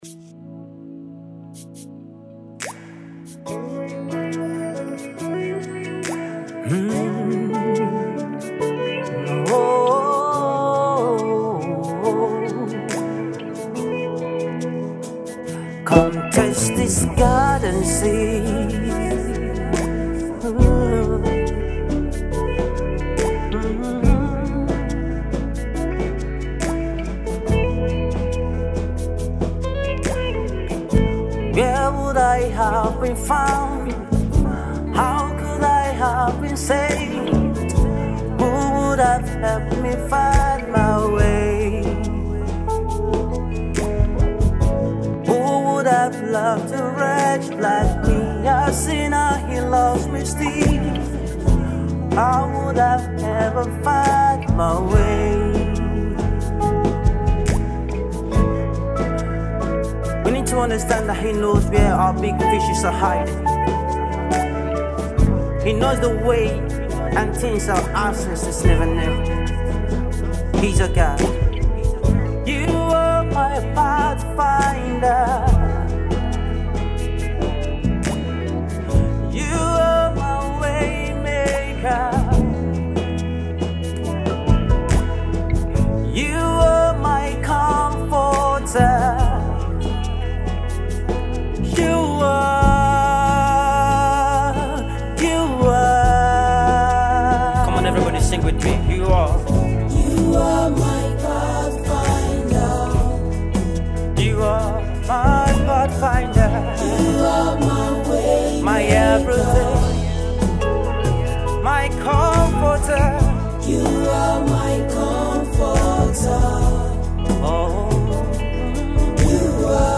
a soft rock artist, singer/songwriter and music minister